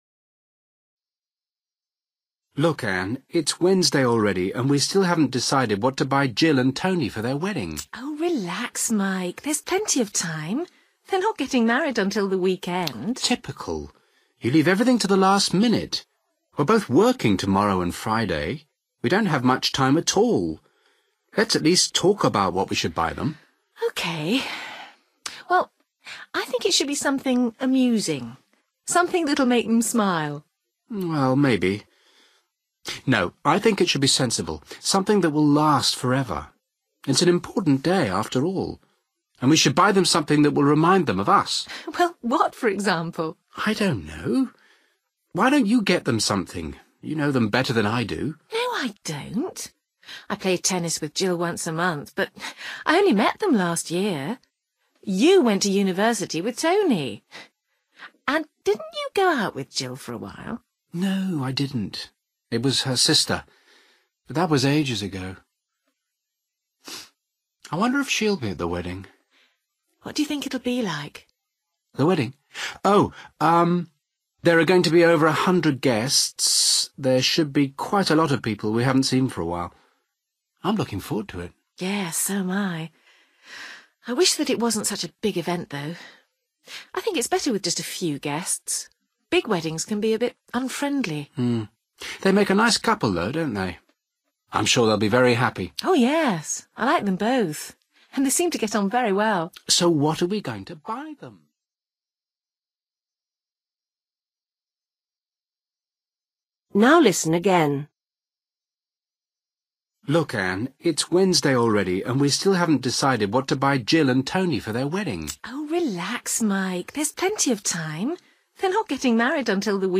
You will hear a conversation between a young man, Mike, and his sister, Ann.